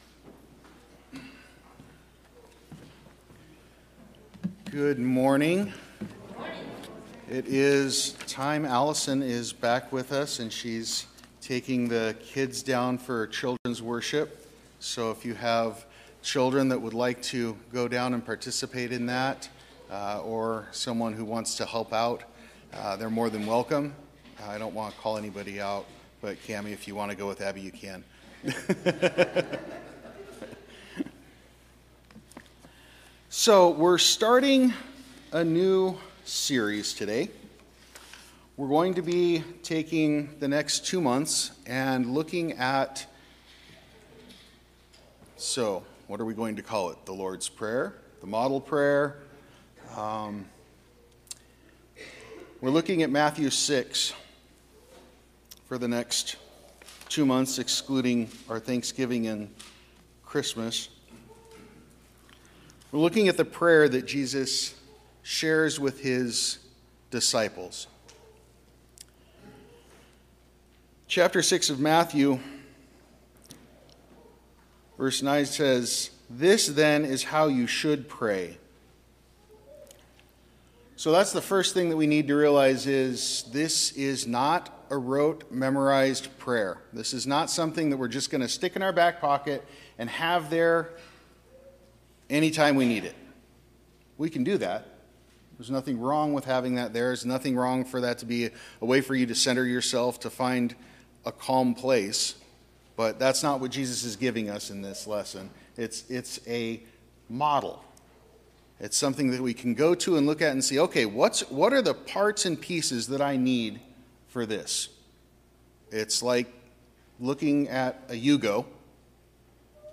Sermon-Audio-November-5-2023.mp3